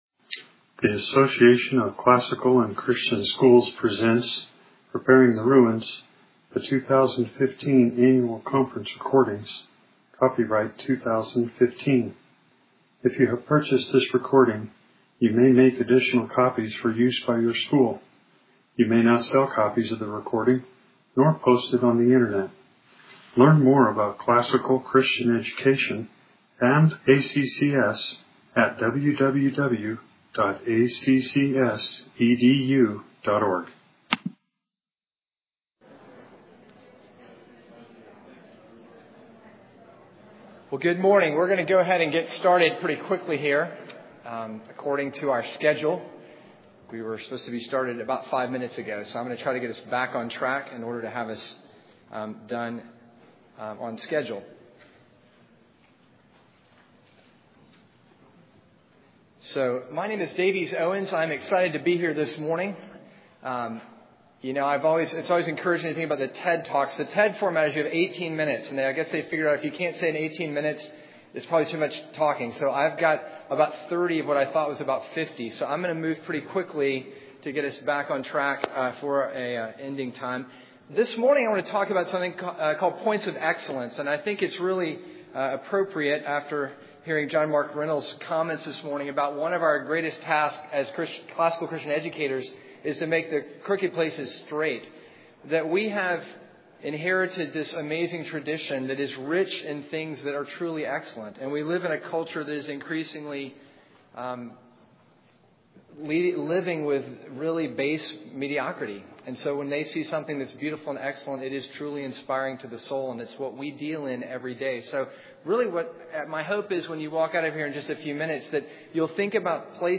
Jan 11, 2019 | Conference Talks, Foundations Talk, Fundraising & Development, Library, Marketing & Growth, Media_Audio, Operations & Facilities | 0 comments